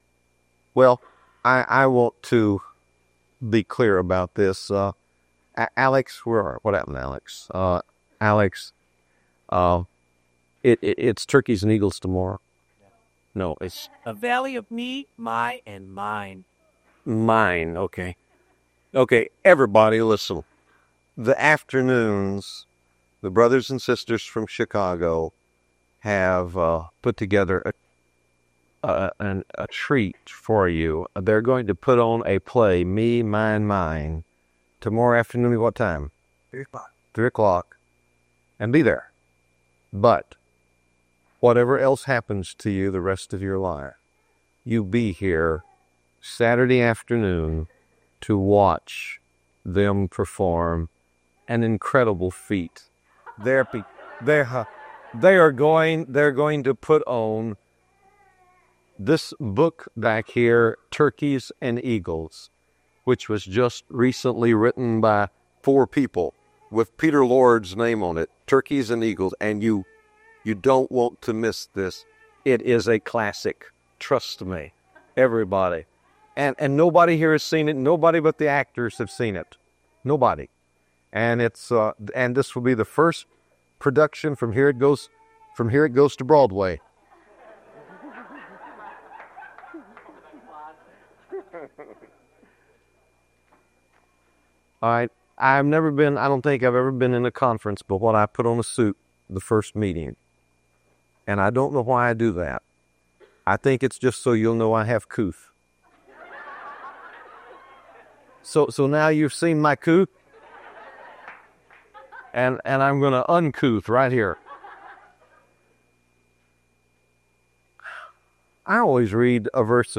Winona Conference Part 1 – Intro to the Spiritual Community